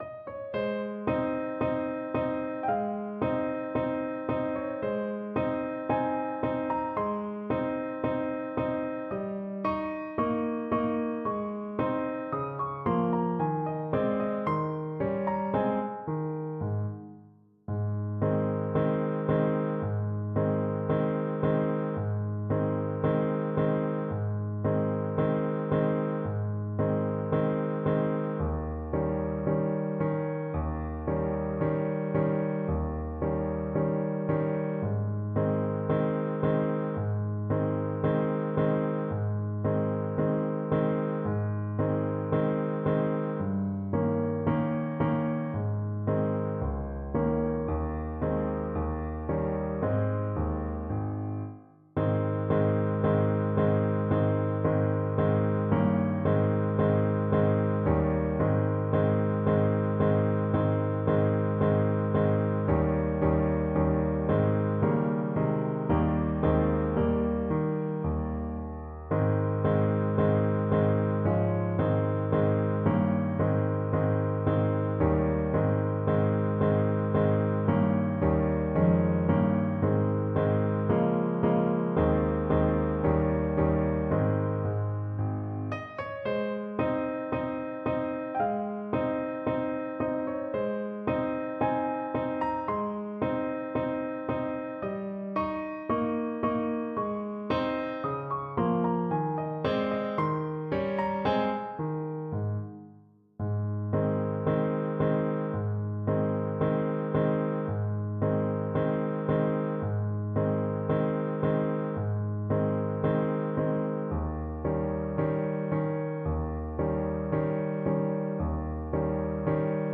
Alto Saxophone